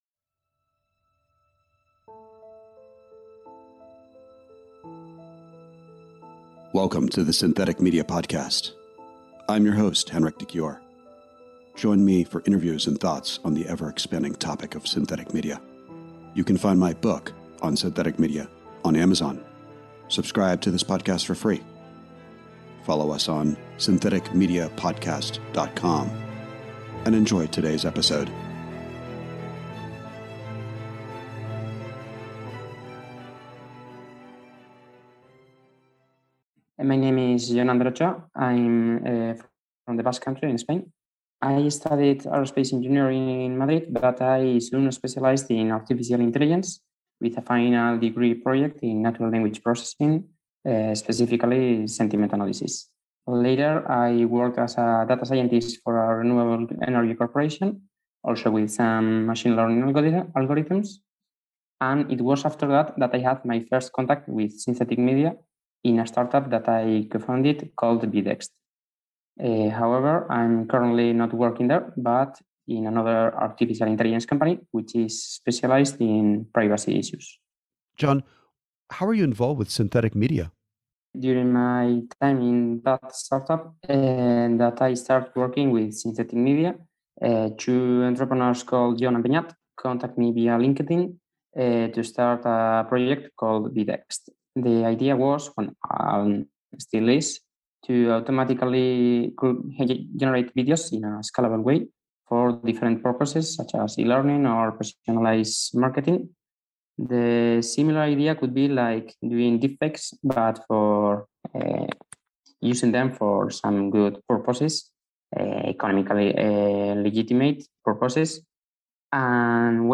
Synthetic Media / Interview